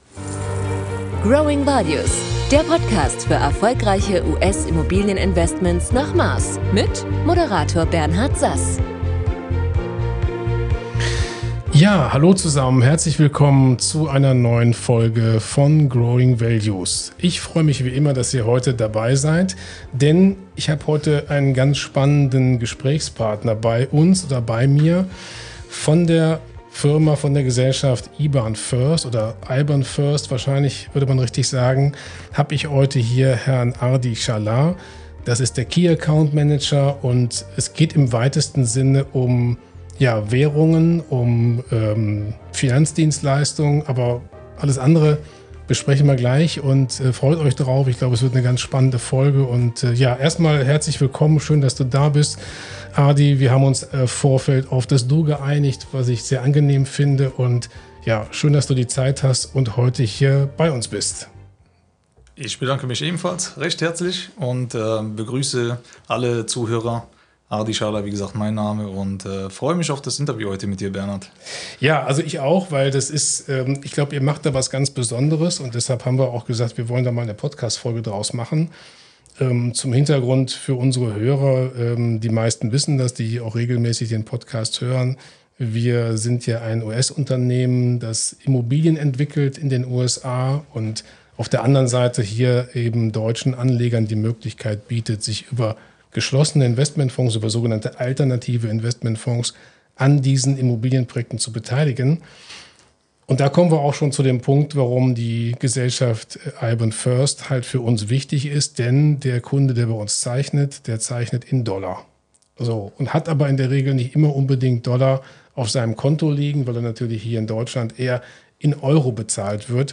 Das war ein sehr informatives und interessantes Gespräch bei uns in Düsseldorf!